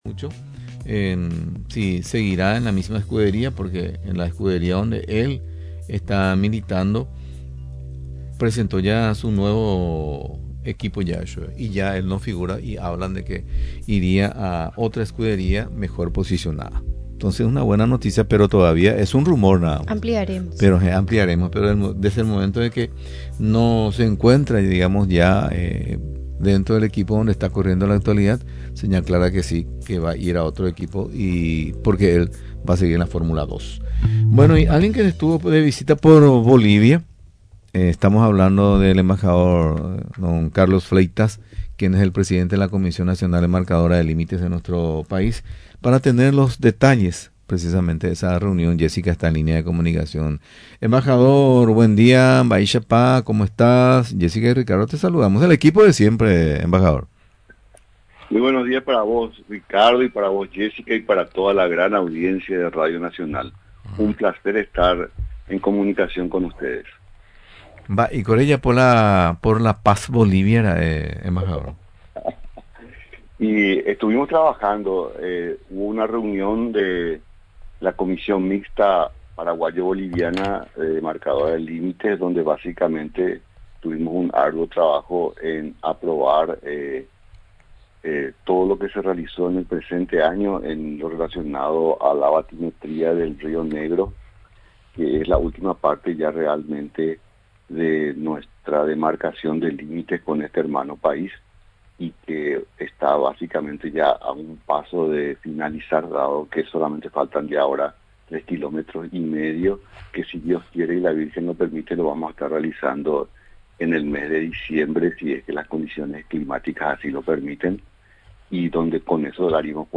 Paraguay y Bolivia están a punto de finalizar la demarcación total de los límite, destacó este viernes el presidente de la Comisión Nacional Demarcadora de Límites, Carlos Fleitas.